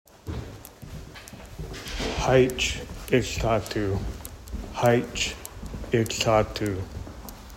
Learn how to pronounce haich ikt’at’uu.